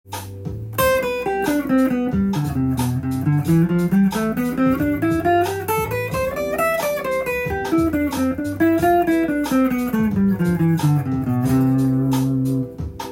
３連符でも弾いてきます。